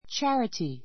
charity tʃǽrəti チャ リティ 名詞 複 charities tʃǽrətiz チャ リティ ズ ❶ 慈善 じぜん , 施 ほどこ し; 思いやり a charity concert a charity concert チャリティーコンサート Charity begins at home.